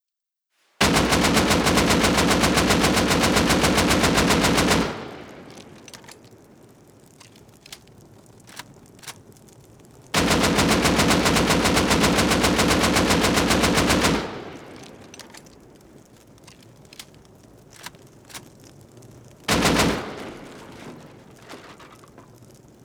Shooting Down Sheet Metal